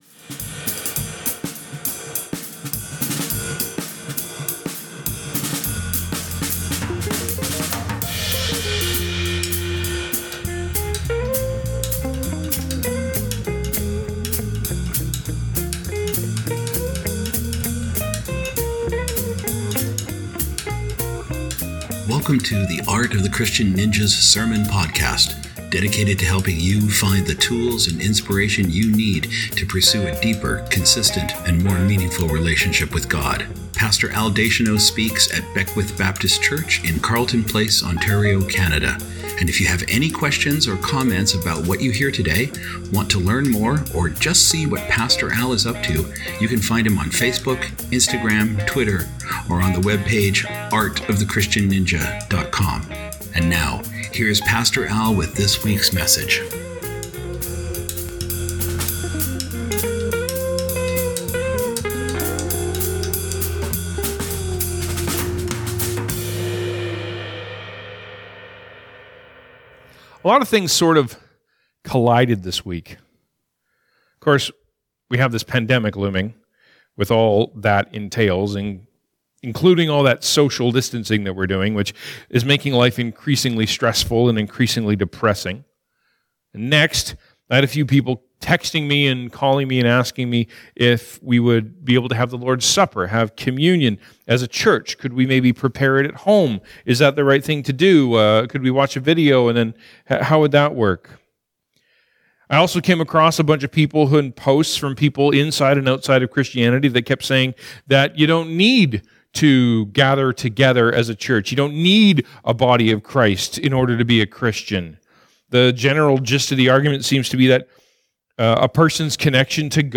racism-sermon.mp3